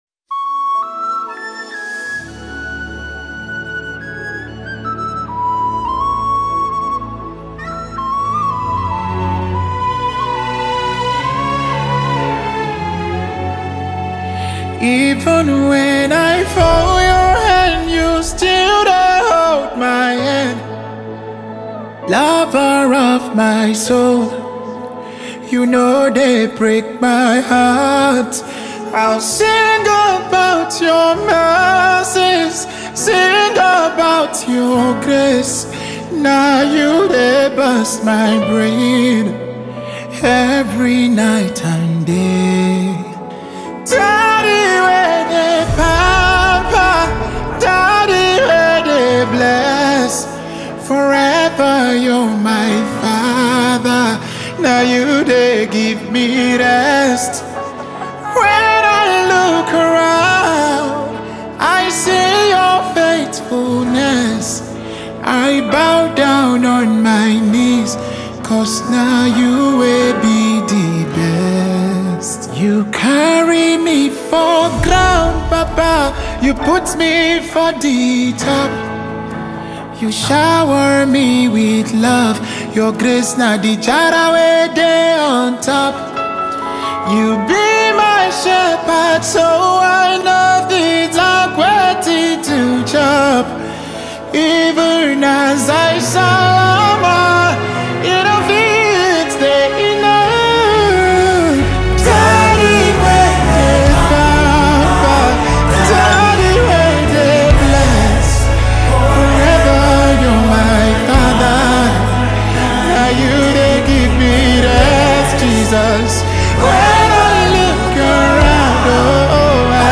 deep, special worship version